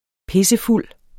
Udtale [ ˈpesəˈfulˀ ]